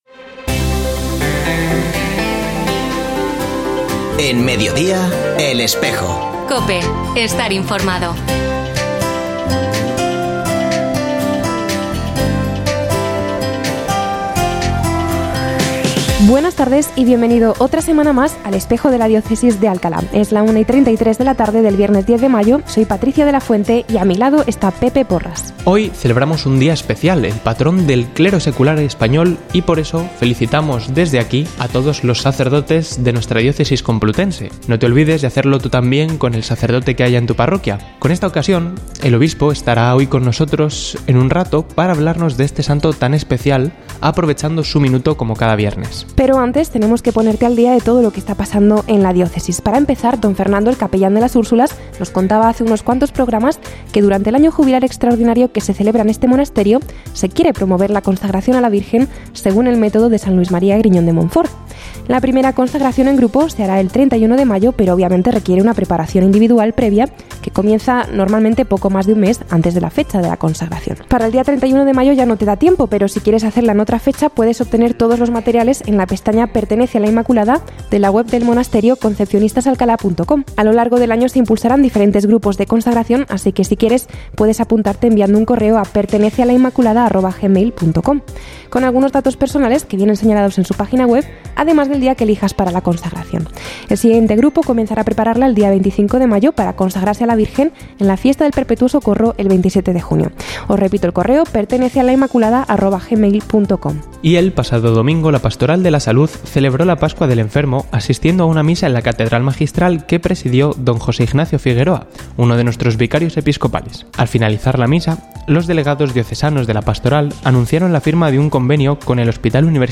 Ofrecemos el audio del programa de El Espejo de la Diócesis de Alcalá emitido hoy, 10 de mayo de 2024, en radio COPE. Este espacio de información religiosa de nuestra diócesis puede escucharse en la frecuencia 92.0 FM, todos los viernes de 13.33 a 14 horas.